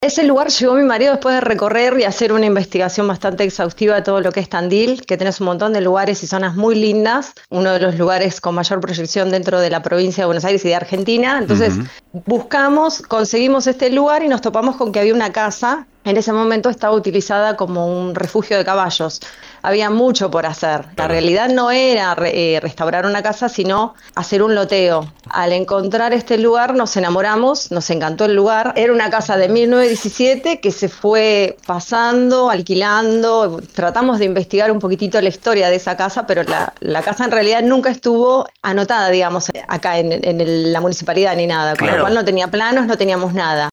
desde FM Ilusiones conversamos con